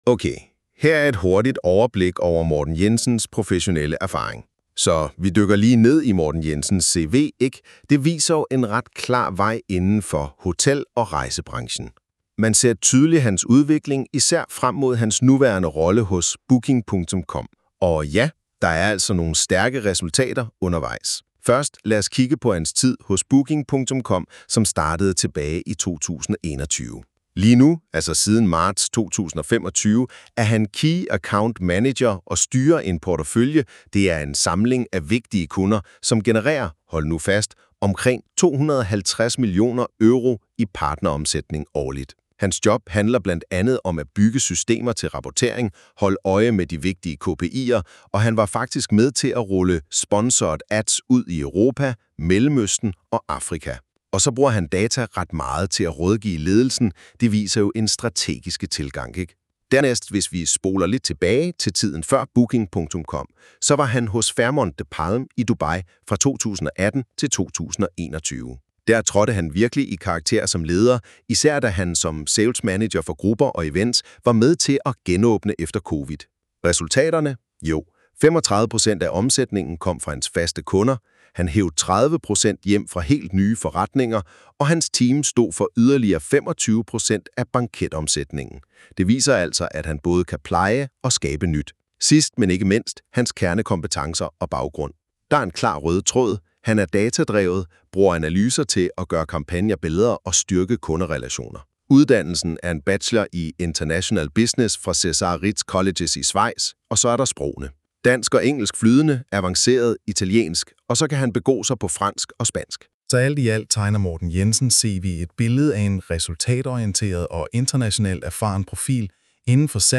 Audio CV 🔊 (English)
The CV recording is generated on NotebookLM - a fun take on presenting a professional background but as with many AI tools, it’s not quite flawless